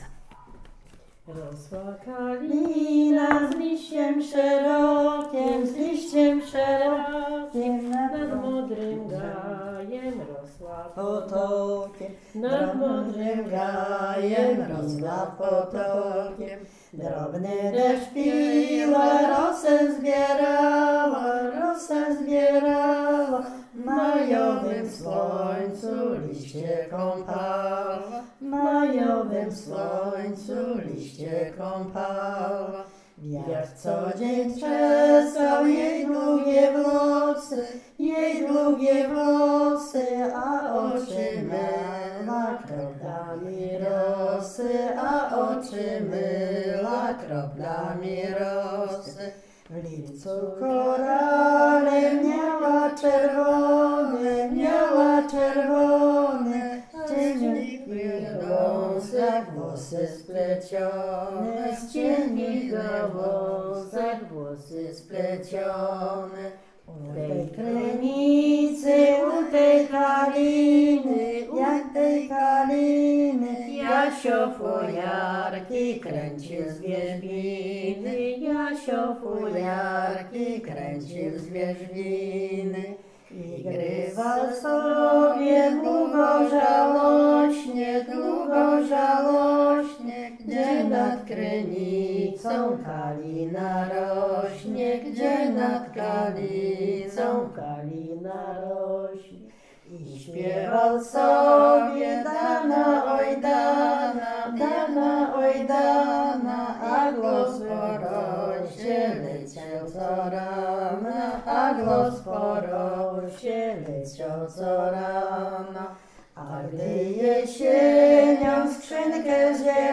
Pieśń "Rosła kalina"
Nagranie wykonano podczas wywiadu